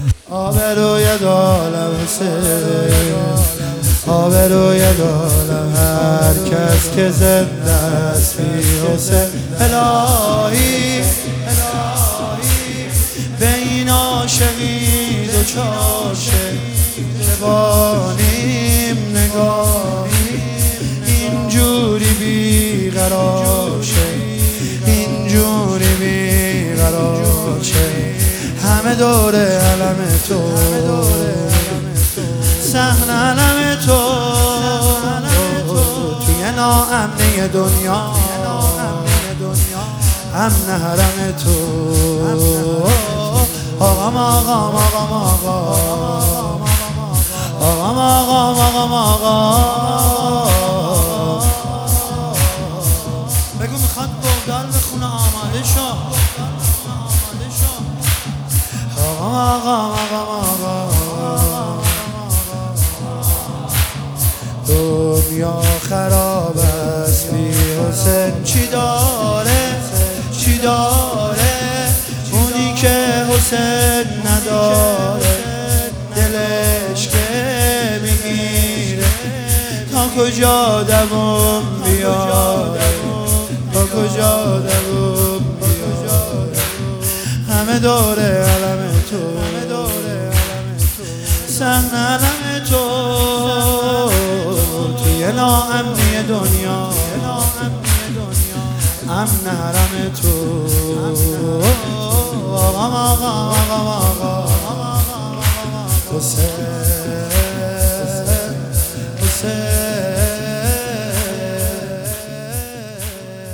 شور شب اول فاطمیه 1403